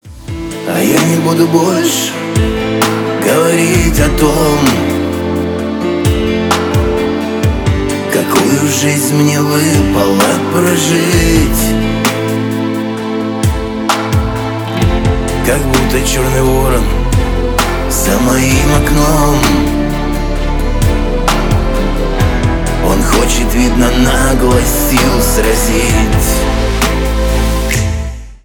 душевные , поп
шансон